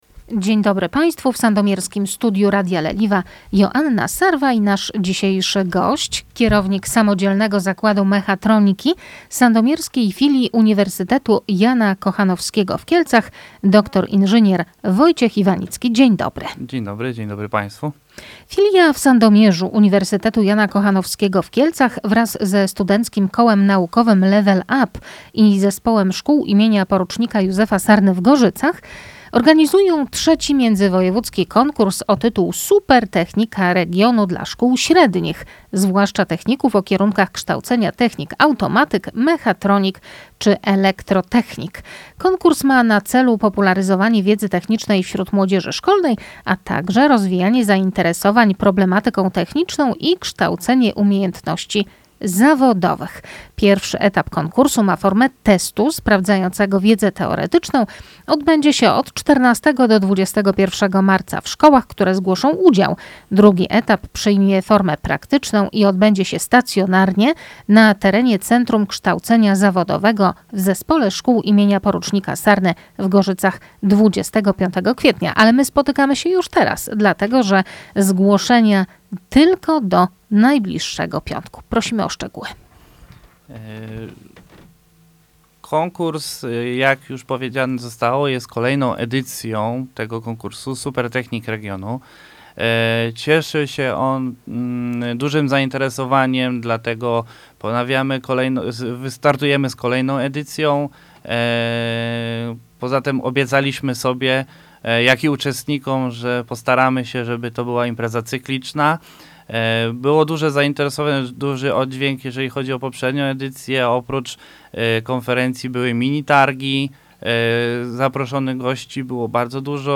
O szczegółach mówi Gość Radia Leliwa